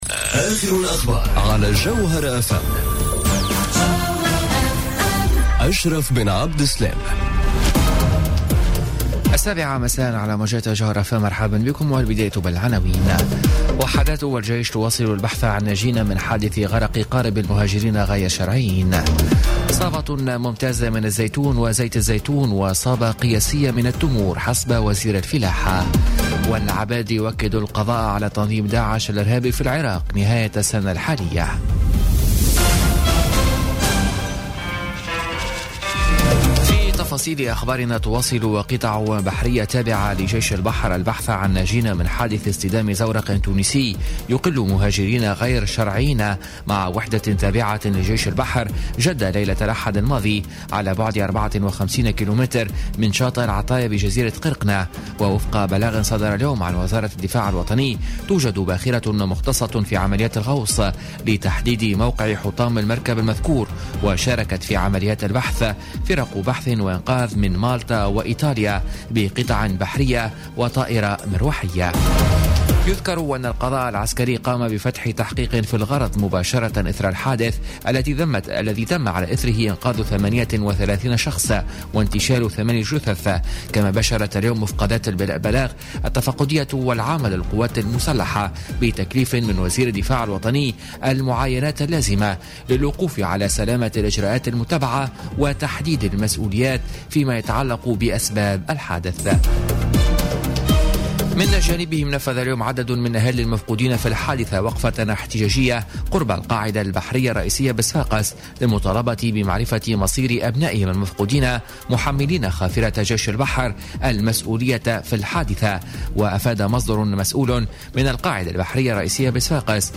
نشرة أخبار السابعة مساء ليوم الثلاثاء 10 أكتوبر 2017